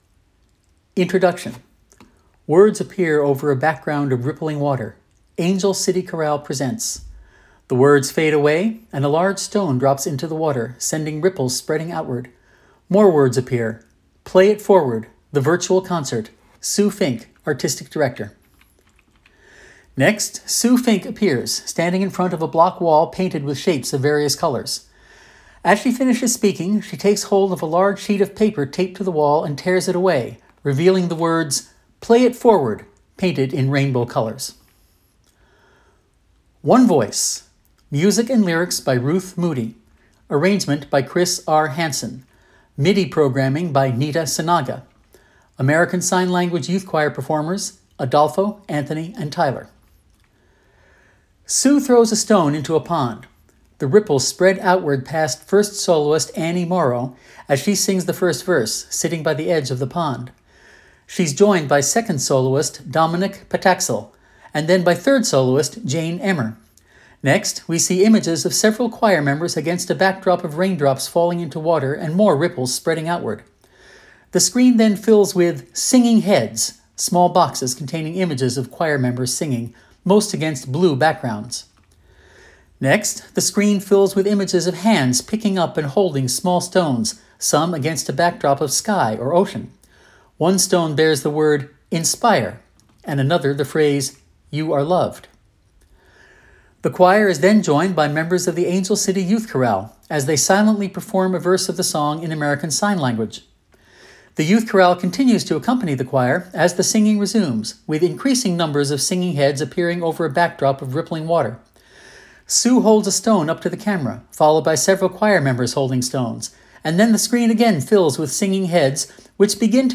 This page contains descriptive text and audio description of the Angel City Chorale Concert from June 2021.
ACC-PIF-Audio-Description.mp3